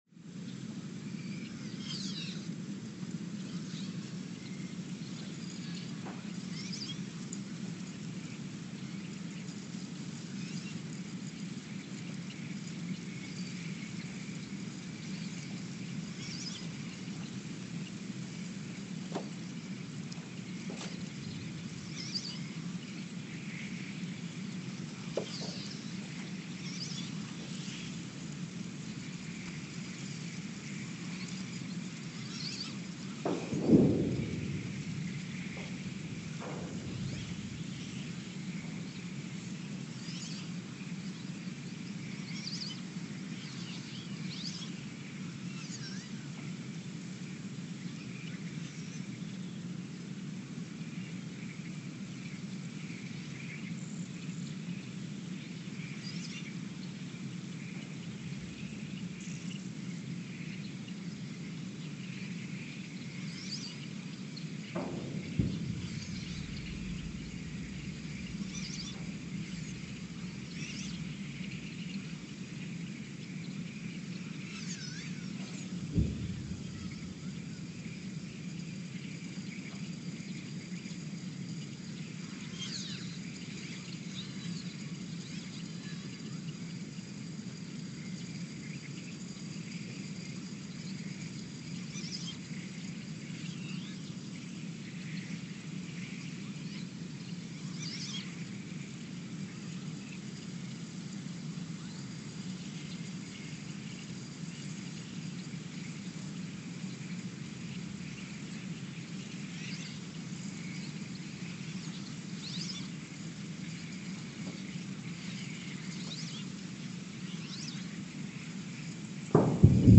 Ulaanbaatar, Mongolia (seismic) archived on April 13, 2024
Station : ULN (network: IRIS/USGS) at Ulaanbaatar, Mongolia
Sensor : STS-1V/VBB
Speedup : ×900 (transposed up about 10 octaves)
Gain correction : 25dB
SoX post-processing : highpass -2 90 highpass -2 90